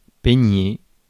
Ääntäminen
IPA: [pe.ɲe]